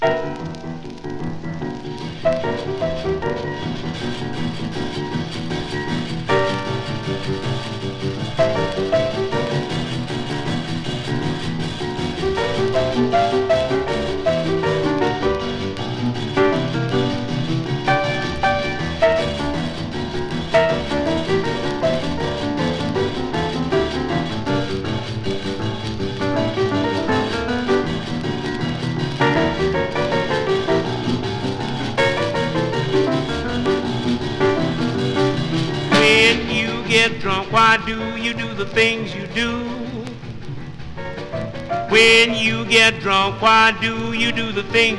Jazz, Blues, Swing, Big Band　USA　12inchレコード　33rpm　Mono